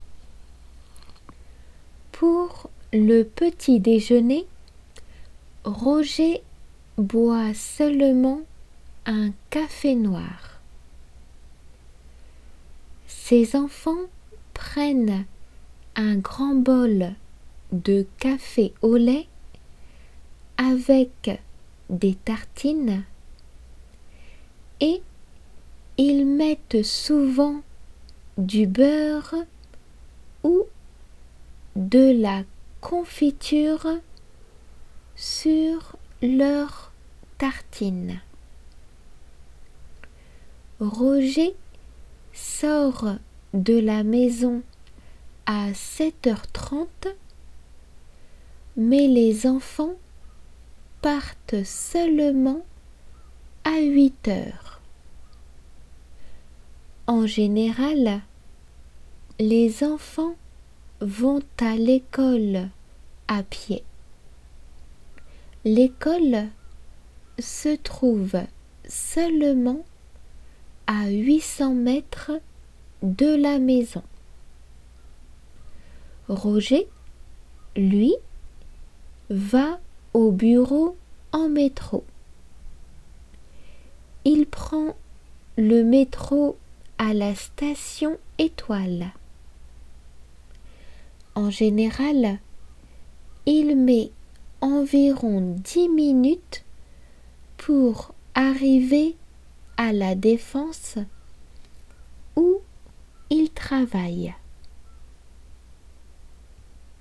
練習用です。　実際の仏検とは読む回数が異なります。
普通の速さで。
デイクテの速さで。